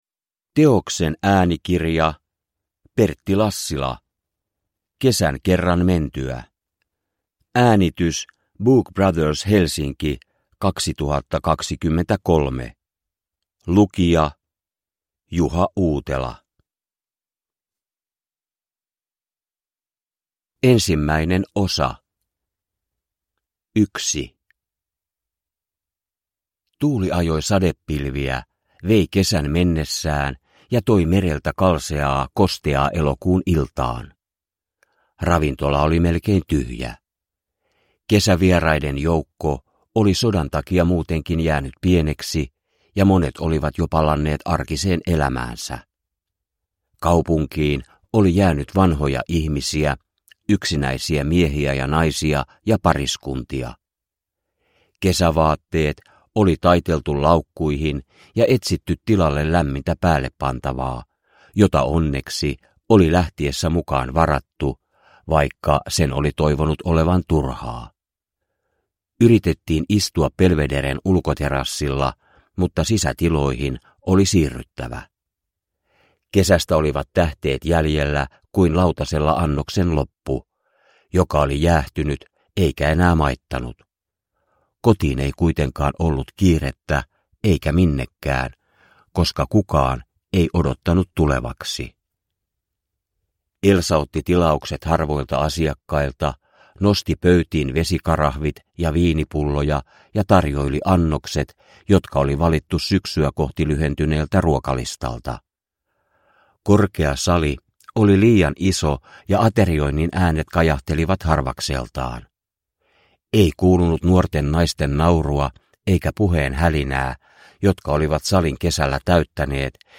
Kesän kerran mentyä – Ljudbok – Laddas ner